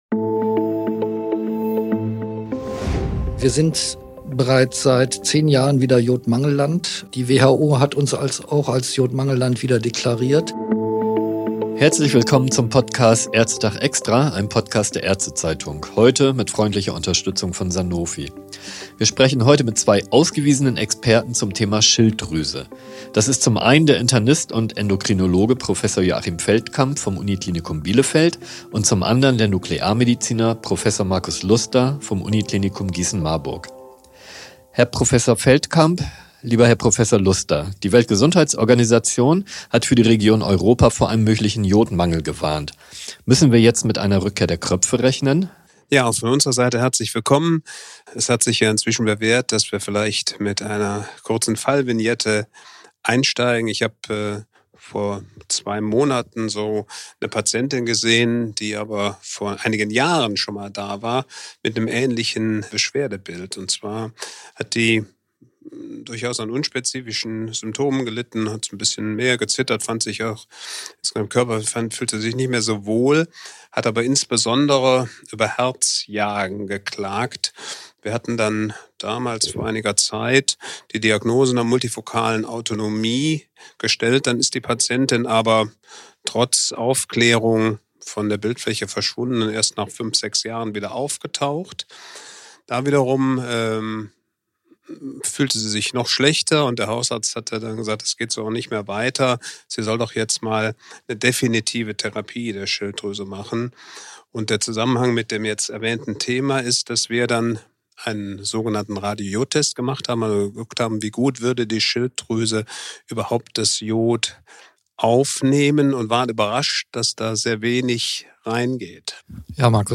Welche Maßnahmen das sind, und warum es in Europa überhaupt zu einer Mangelversorgung mit Jod kommt, wird in diesem Podcast diskutiert. Dabei geht es auch darum, wie der Zusammenhang von Jodversorgung und sogenannten Kröpfen entdeckt wurde und welche Rolle dabei die Schweiz gespielt hat. Das Thema diskutieren in einem lockeren und auch kurzweiligen Zwiegespräch